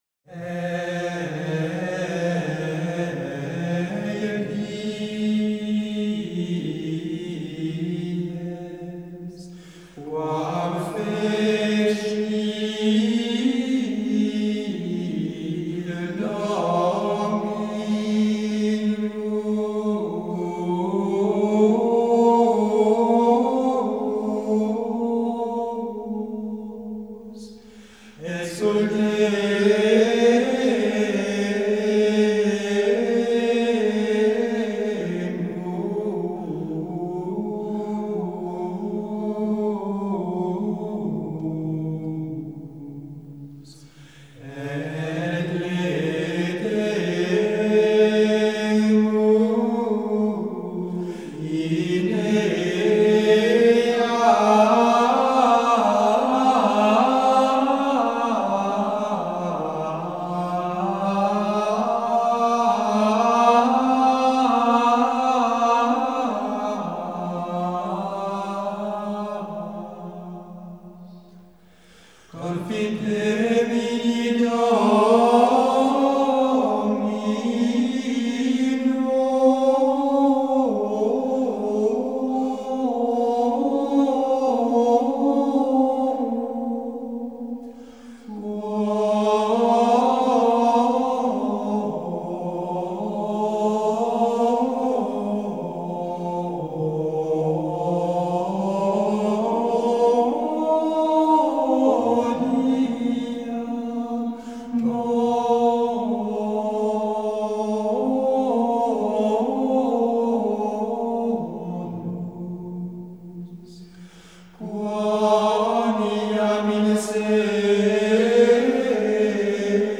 a recording made in the Abbey in April 1995